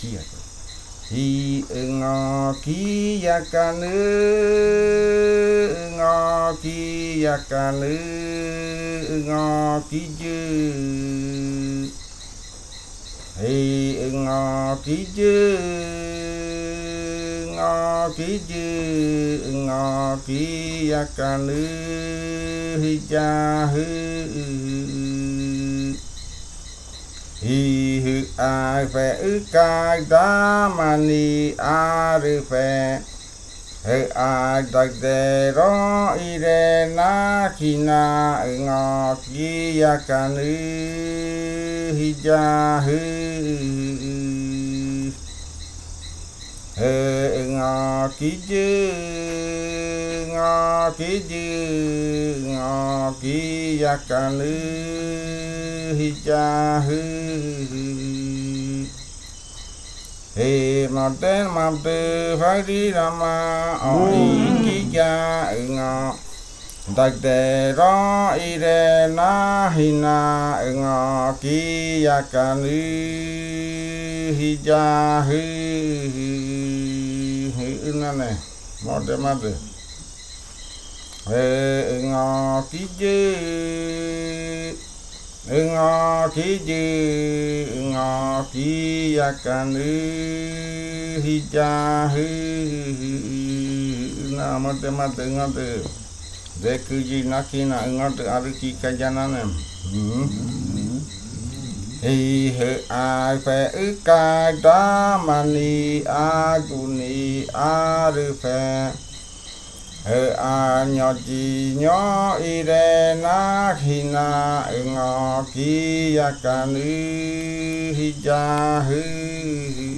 Leticia, Amazonas, (Colombia)
Grupo de danza Kaɨ Komuiya Uai
Canto fakariya de la variante Jimokɨ (cantos de guerrero).
Fakariya chant of The Jimokɨ variant (Warrior chants).
Flautas de Pan y cantos de fakariya del grupo Kaɨ Komuiya Uai